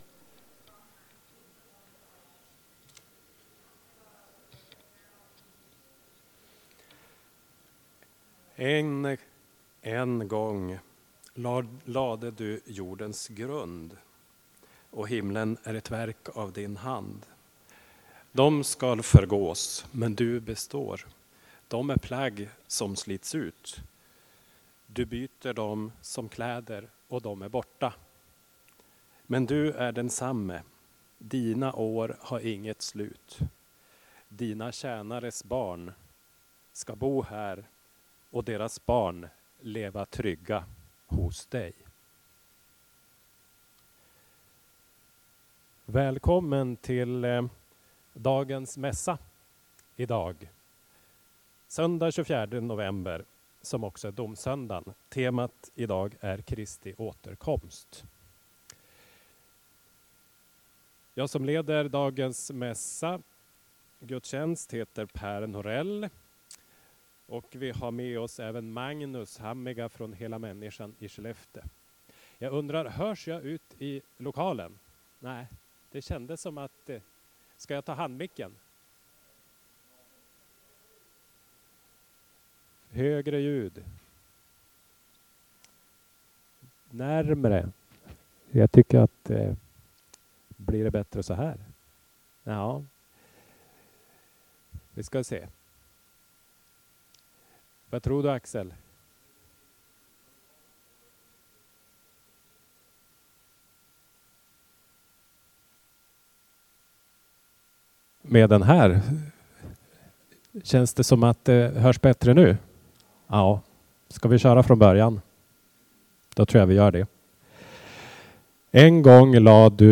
På domssöndagen den 24 november hölls mässa i EFS-kyrkan. Temat var: Kristi återkomst.